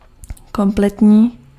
Ääntäminen
IPA: [kɔ̃.plɛ]